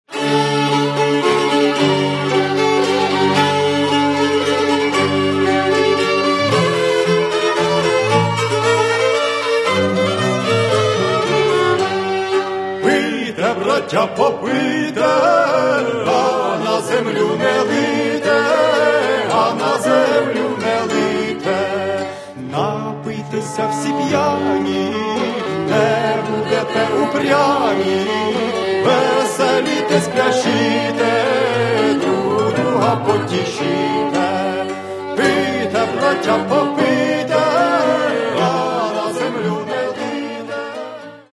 бенкетний кант